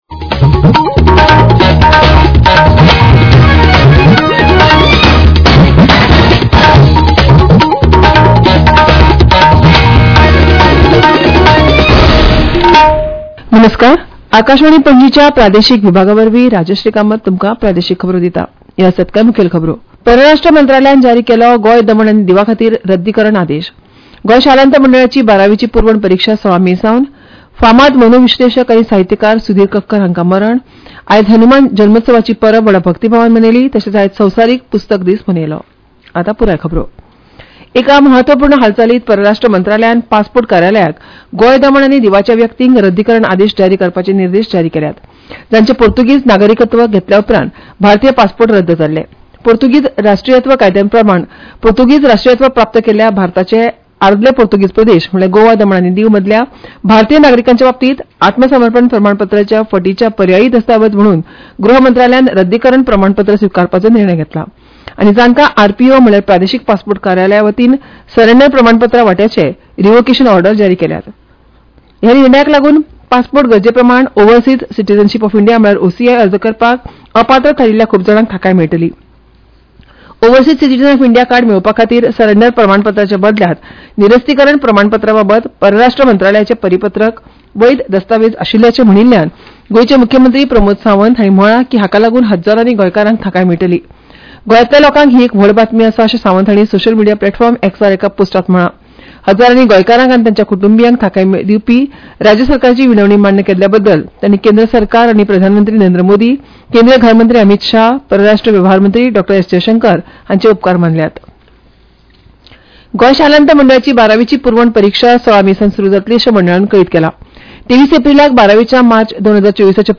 Transcript summary Play Audio Midday News